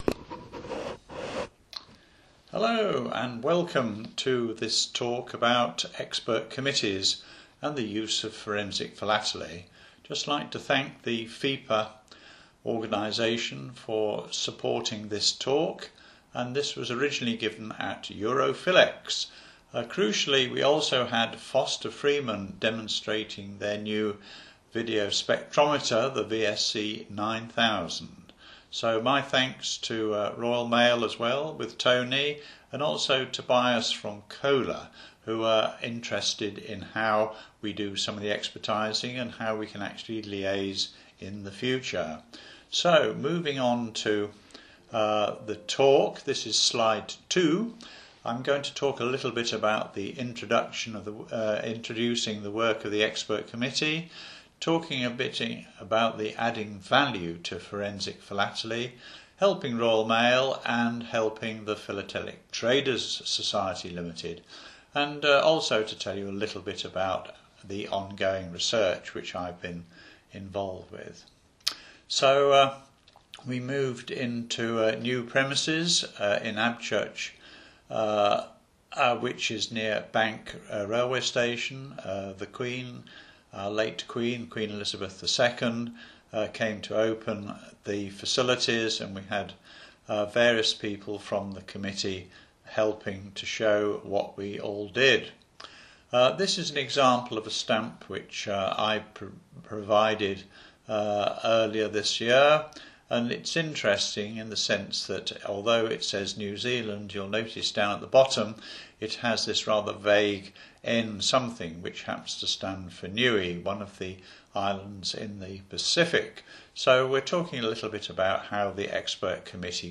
audio quality is poor